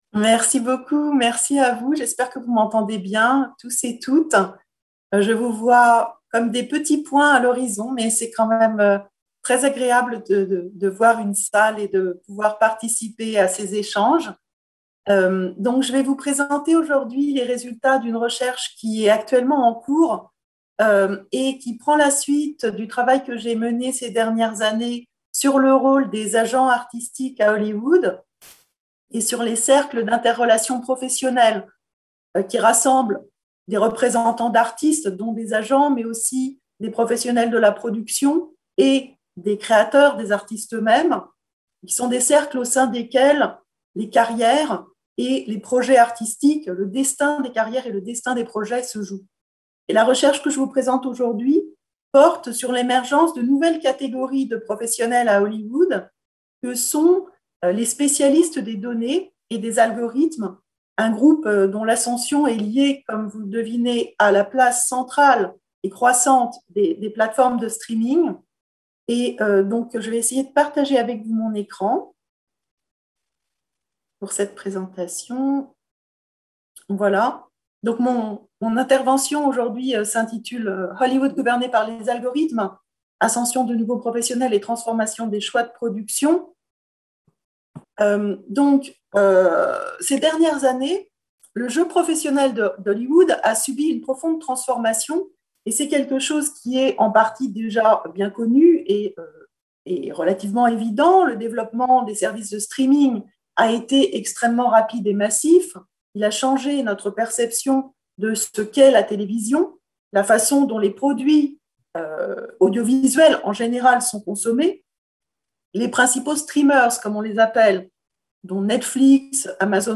Colloque